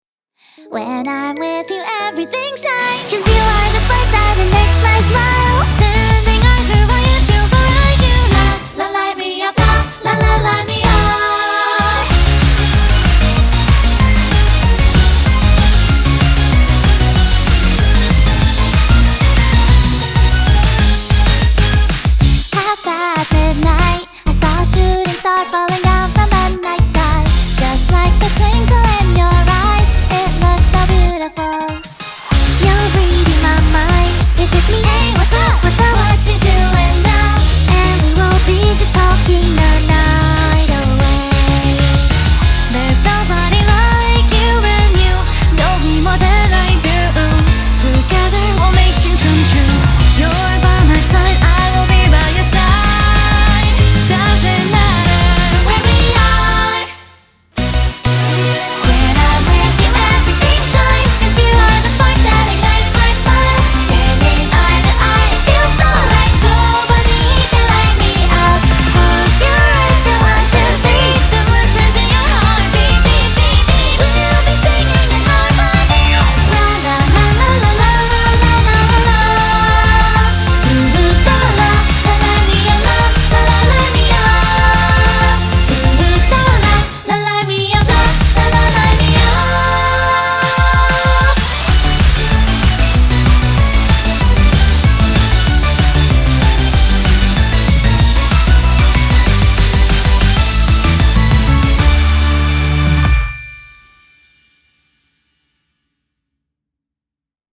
BPM190
MP3 QualityMusic Cut